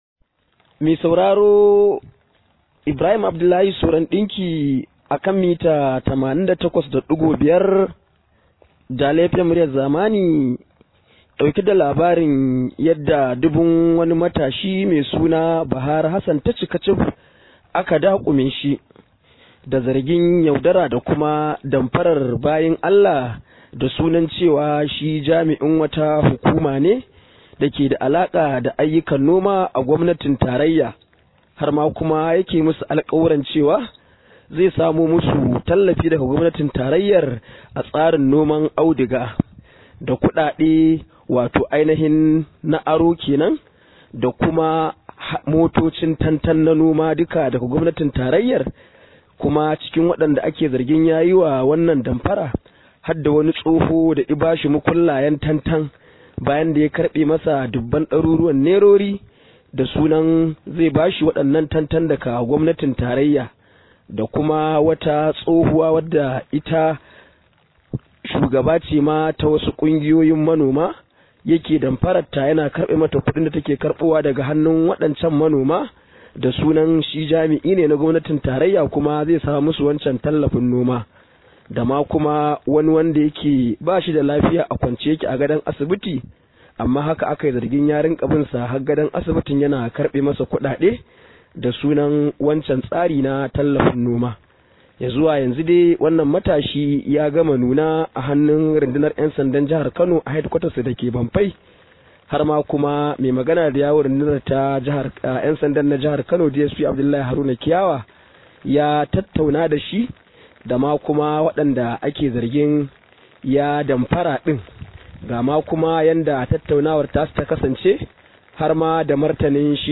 Rahoto: ‘Yan sanda sun kama matashin da ya damfari mutane